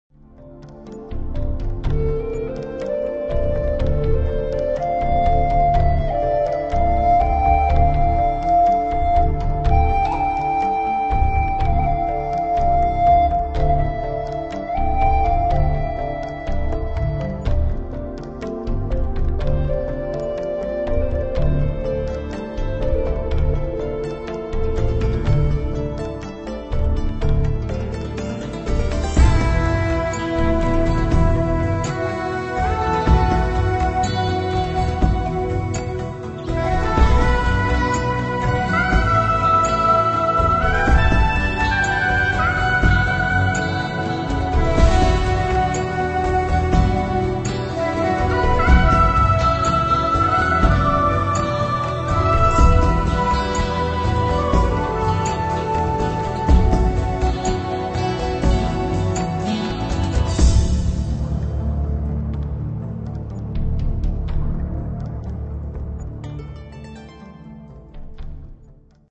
è un evocativo brano New Age